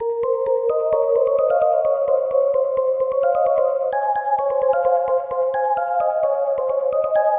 夜晚的钟声
描述：采用果味循环，酷炫的陷阱钟声
标签： 130 bpm Trap Loops Bells Loops 1.24 MB wav Key : E
声道立体声